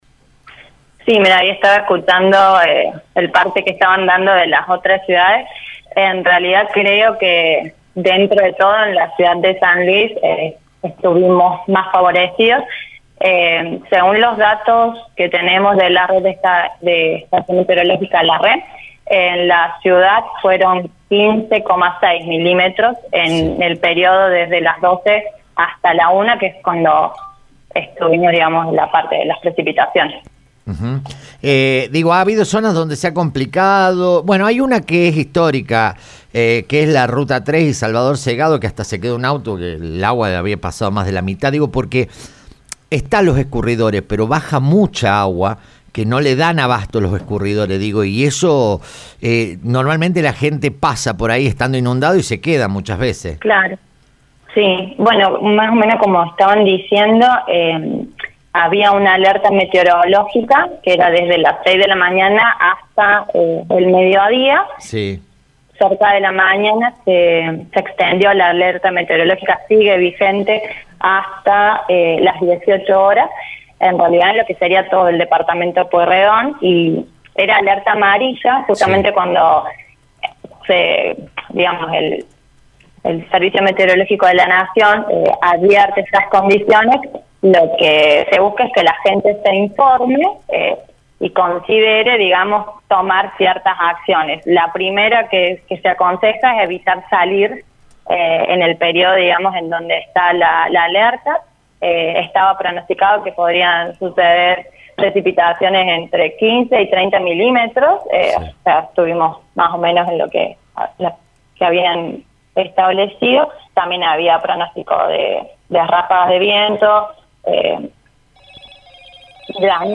La Secretaria de Servicios Públicos, Ing. Yanina Miranda, brindó precisiones sobre el impacto de la tormenta que afectó a la capital puntana este viernes.